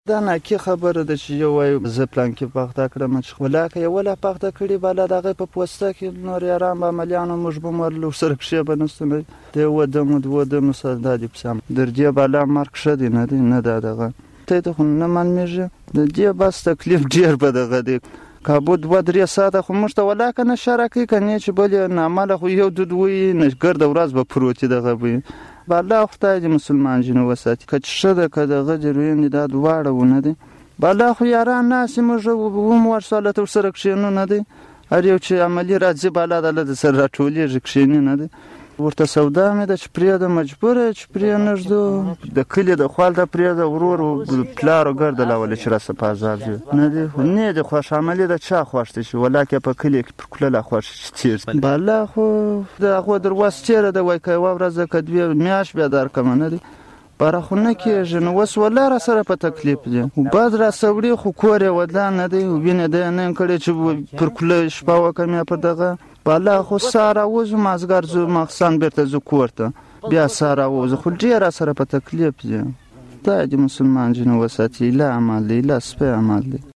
په پوسته کې د معتاد شوي کس سره مرکه